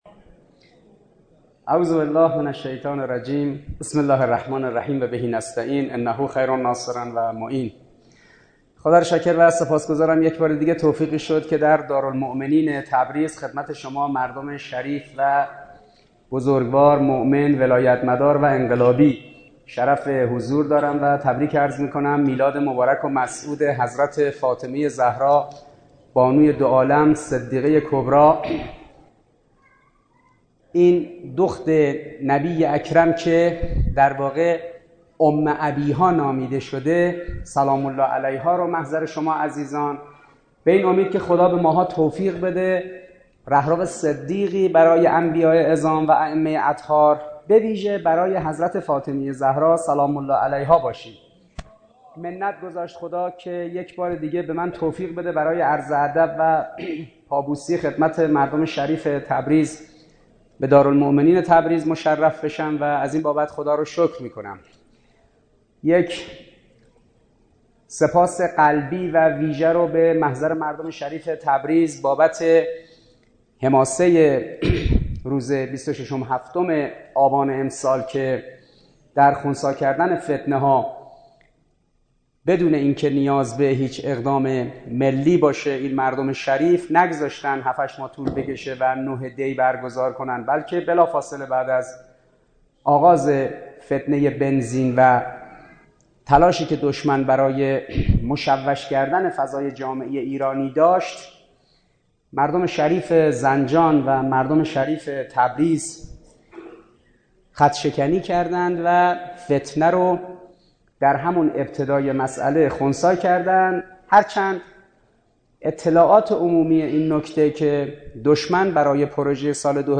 دانلود سخنرانی دکتر حسن عباسی مجلس تراز – تبریز ۲۶ بهمن ۹۸